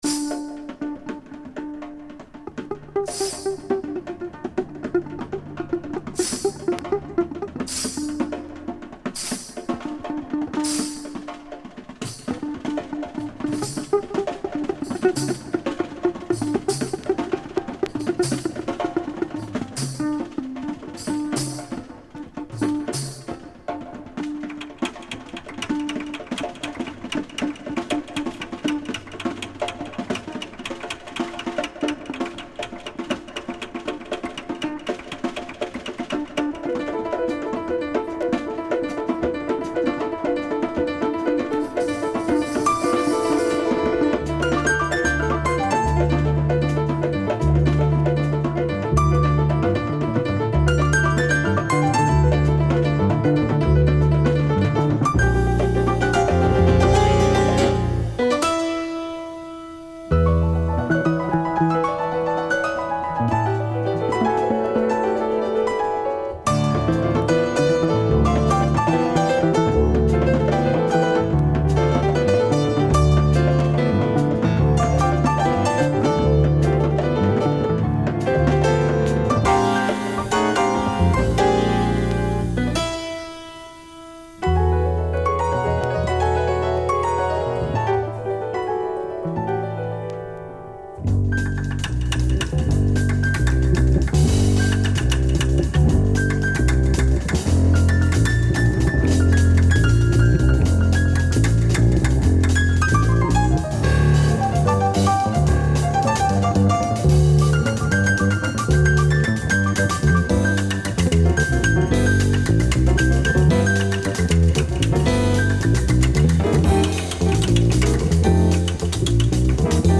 Modal jazz album
pianist
the amazing freaky jazz